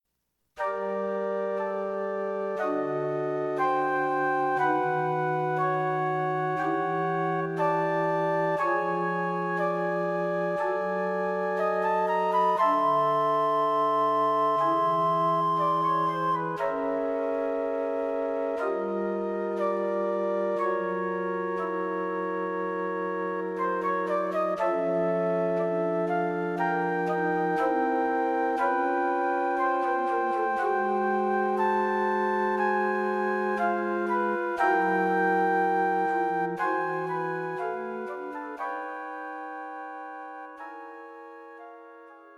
Flute Quartet
A modern, slow-moving piece.
Written for Flutes 1 & 2, Alto Flute and Bass Flute.